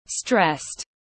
Căng thẳng tiếng anh gọi là stressed, phiên âm tiếng anh đọc là /strest/
Stressed /strest/